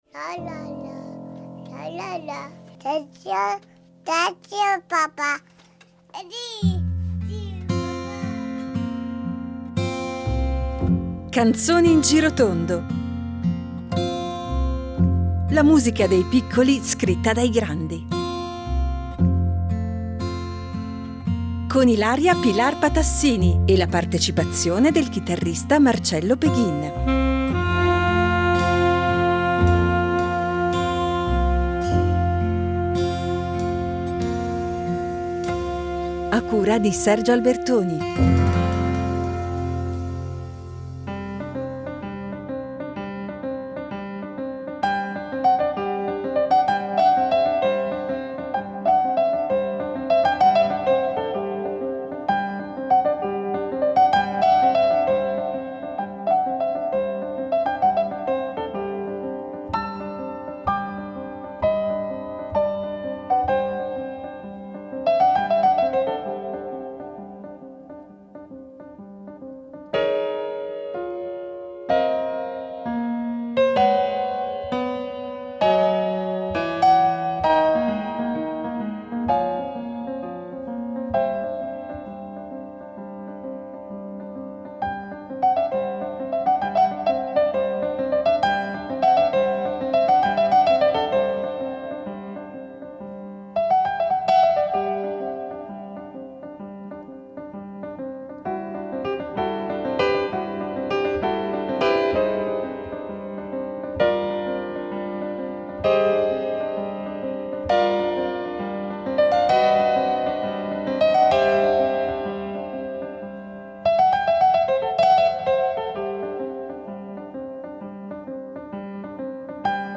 Musica colta e voci bianche